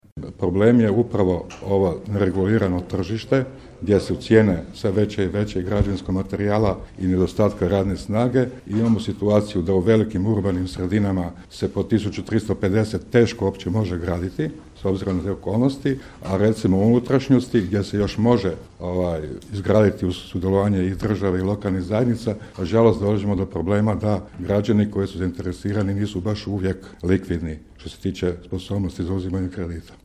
POS stanovi Prelog, potpisivanje ugovora 20.12.2021.